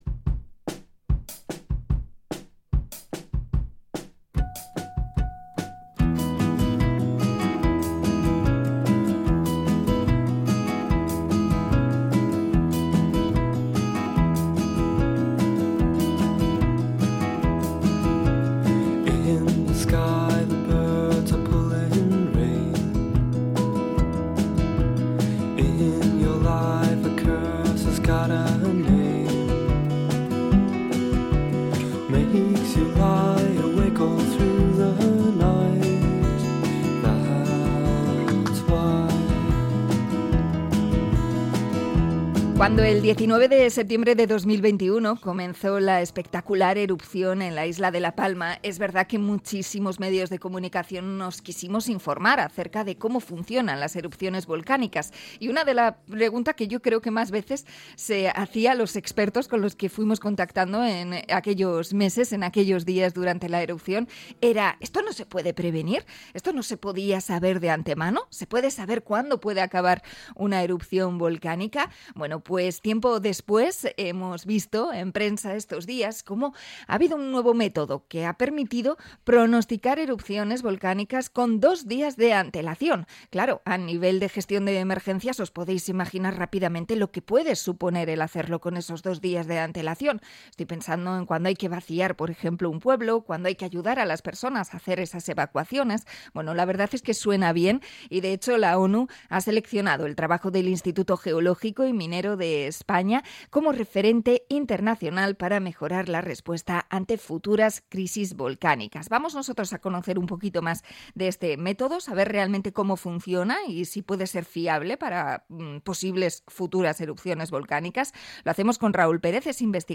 Entrevista a experto en volcanes del CSIC